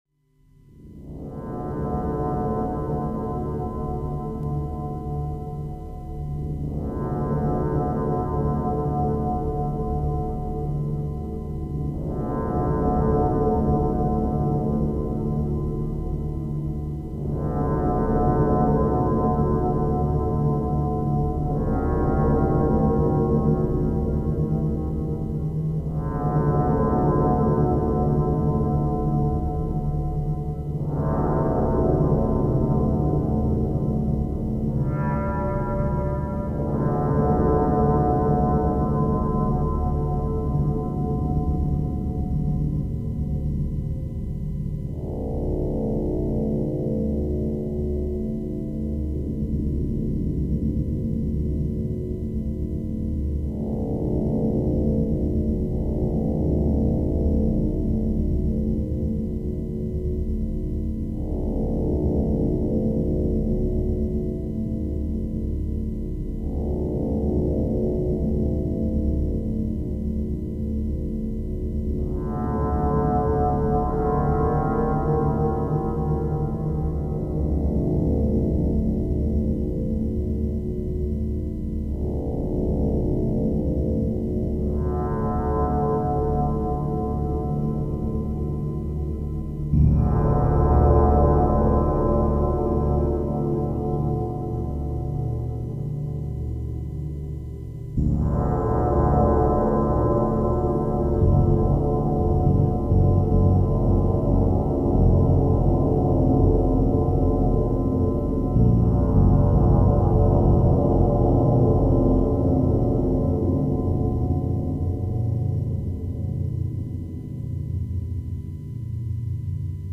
Synthstuff Yamaha DX7
The classic DX7 is a digital FM synthesizer from 1983.
Some sounds from my DX7:
dx7pad2ok.mp3